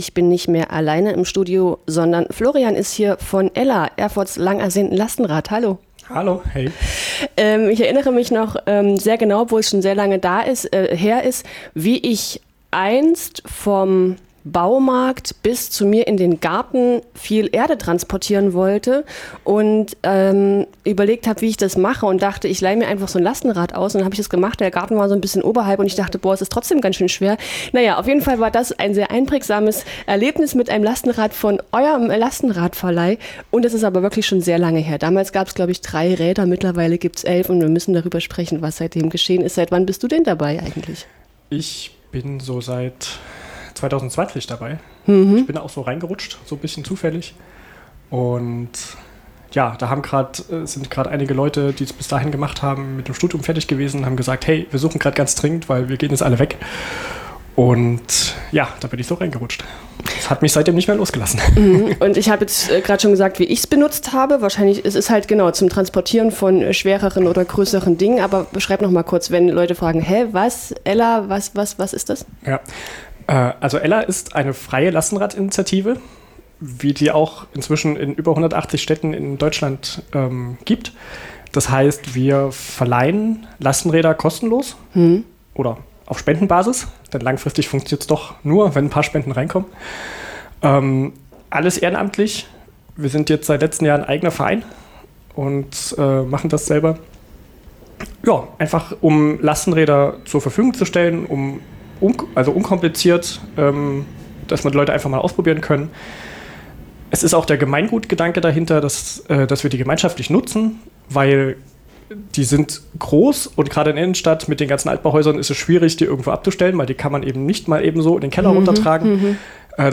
| Die freie Lastenradinitiative Ella im Gespräch